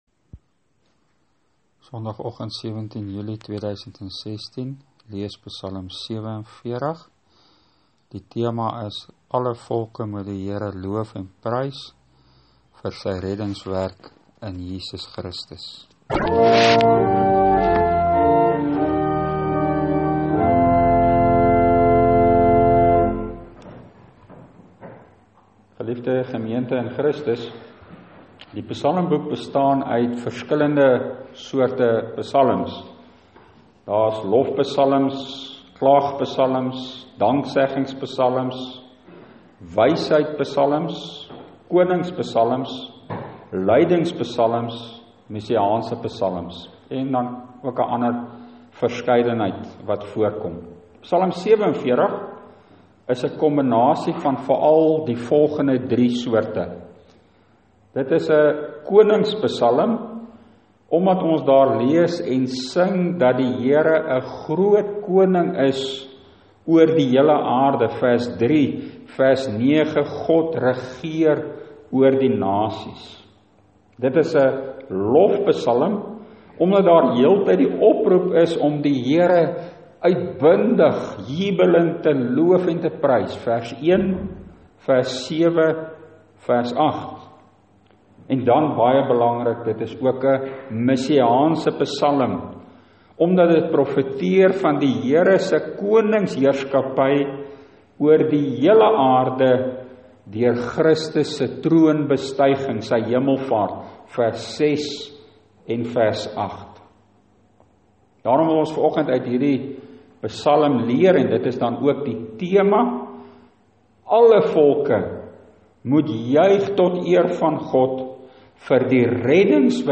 Preek: Psalm 47 Alle volke moet die HERE prys
Preekopname (GK Carletonville, 2016-07-17):